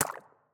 SFX_Slime_Hit_04.wav